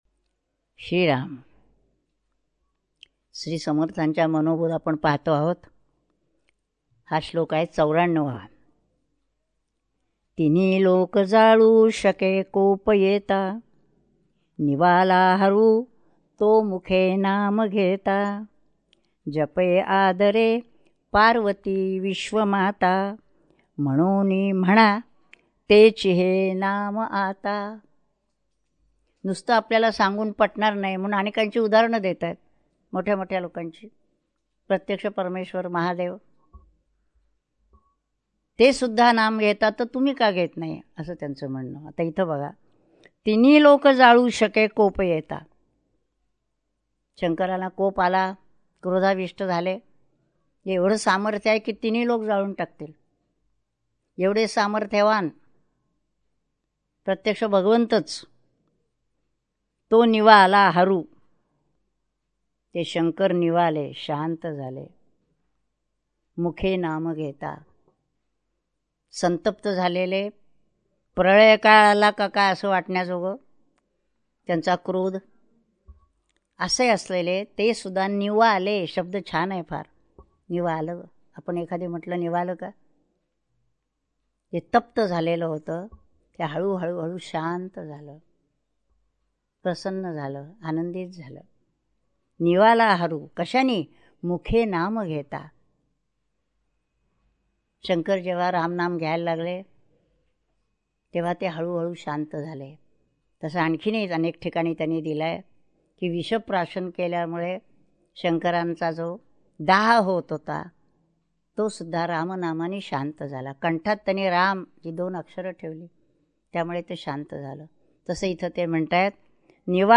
श्री मनाचे श्लोक प्रवचने श्लोक 94 # Shree Manache Shlok Pravachane Shlok 94